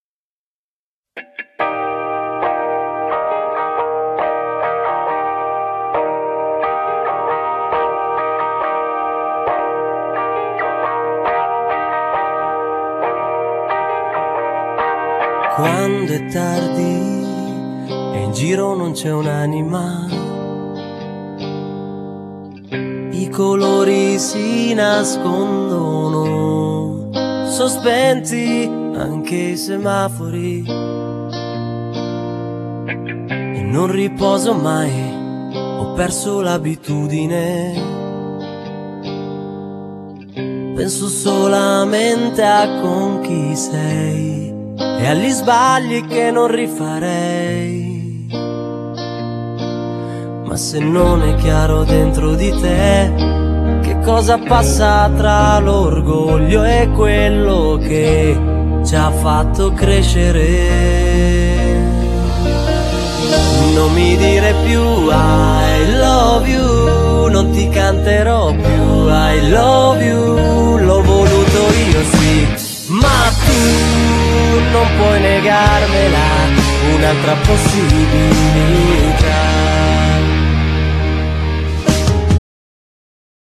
Genere: pop rock
canzone romantica e ben eseguita.